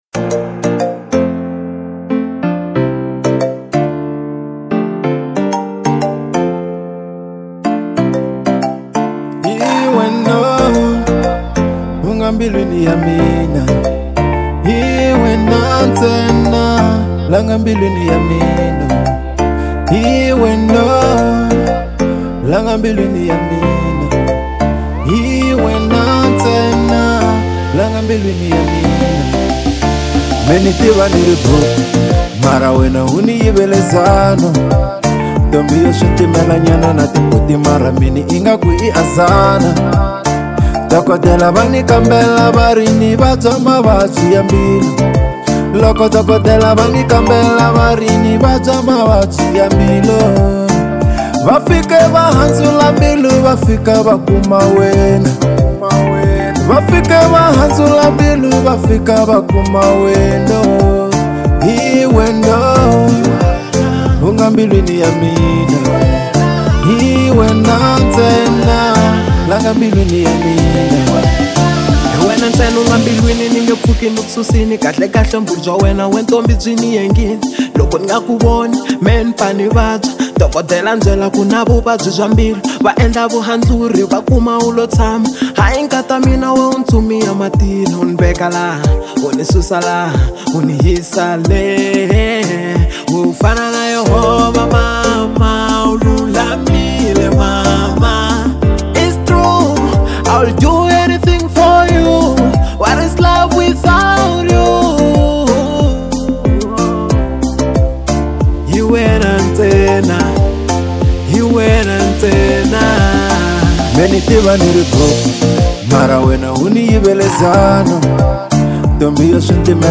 04:24 Genre : Afro Pop Size